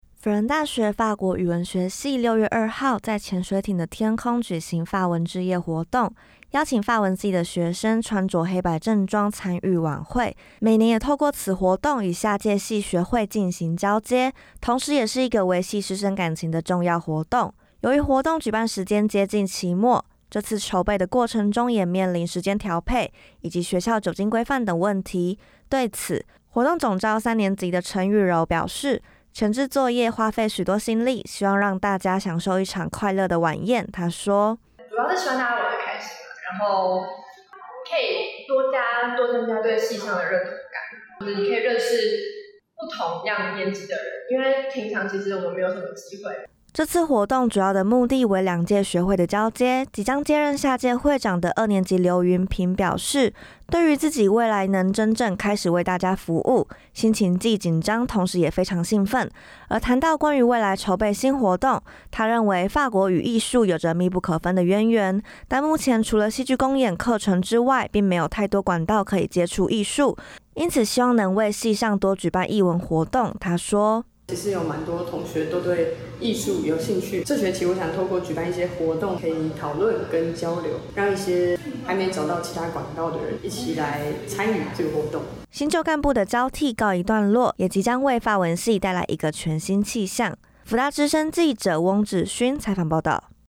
採訪報導） 輔仁大學法國語文學系六月二號在潛水艇的天空舉行法文之夜活動，邀請法文系的學生穿著黑白正裝參與晚會，每年也透過此活動與下屆系學會進行交接，同時也是一個維繫師生感情的重要活動。